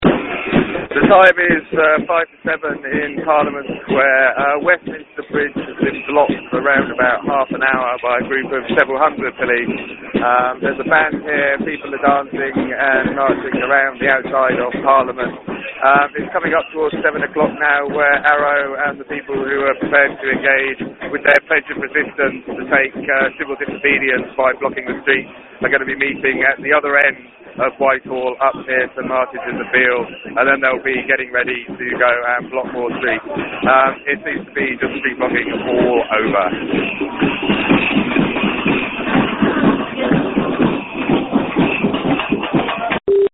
Audio report from protest in parliament square 19:00